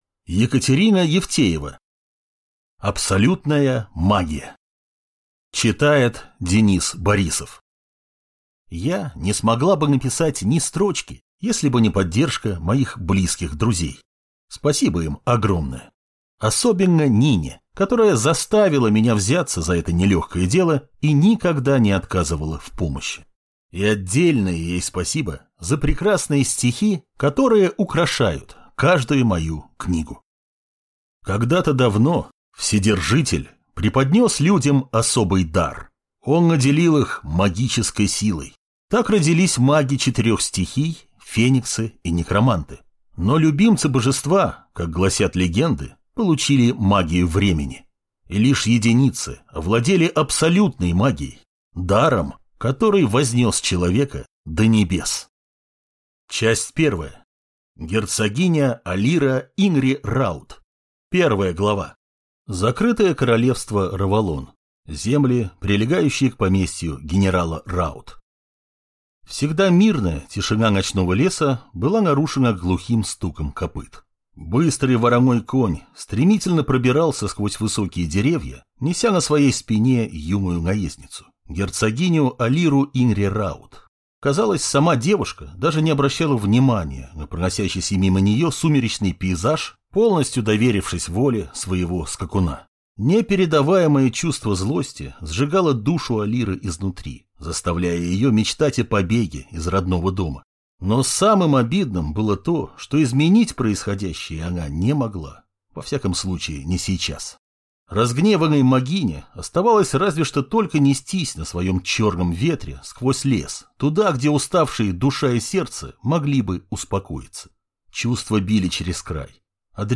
Аудиокнига Абсолютная магия | Библиотека аудиокниг